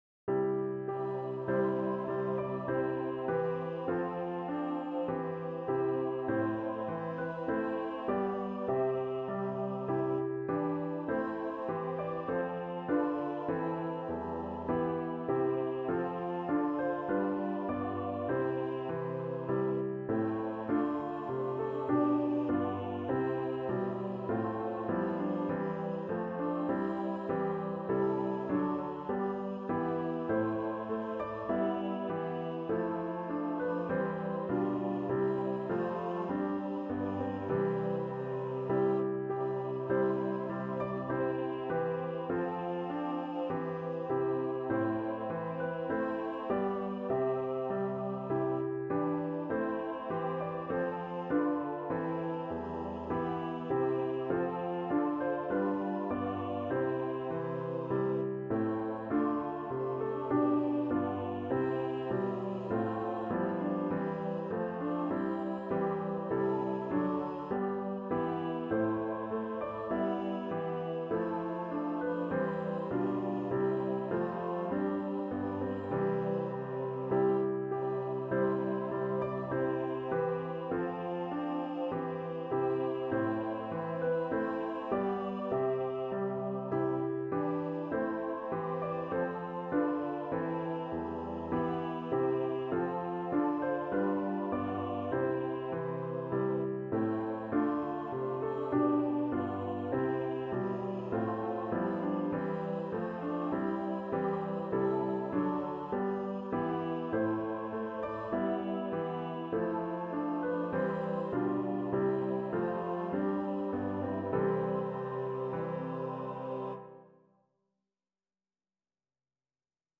A morning hymn from the Dantá De hymnal.